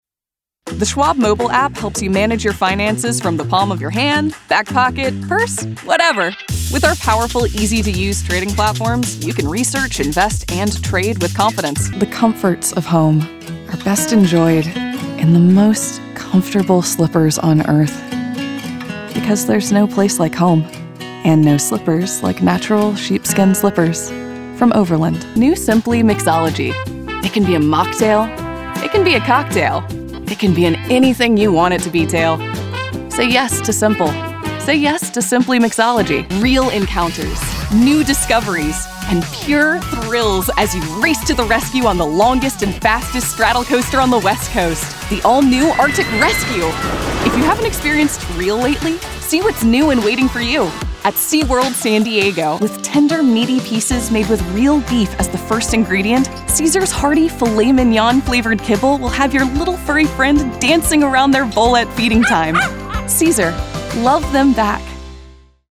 Demo
Teenager, Young Adult, Adult
Has Own Studio
I’m a highly adaptable actor, and I bring warmth, passion, and a touch of brightness to my performances.
russian | character
southern us | natural
standard us | natural